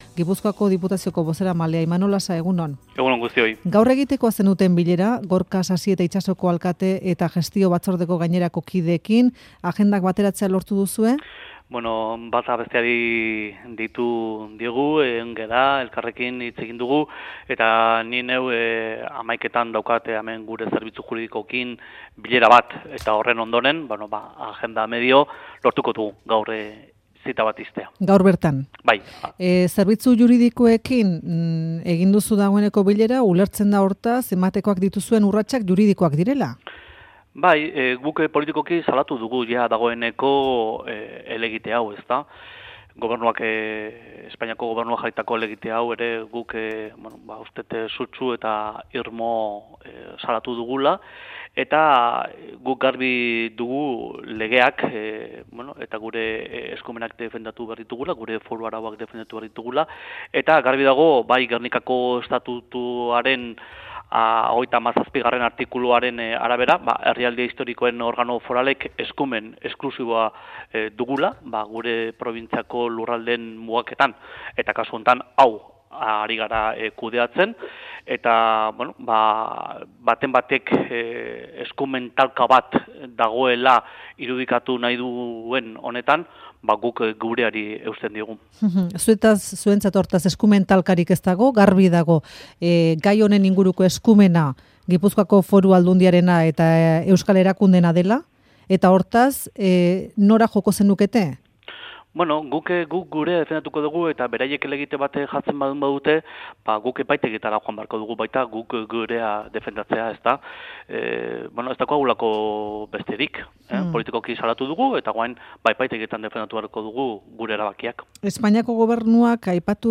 Audioa: Imanol Lasa Gipuzkoako Diputazioko bozeramaleak Euskadi Irratiko Faktorian esan du Itxasok udalerri bezala funtzionatzen jarraituko duela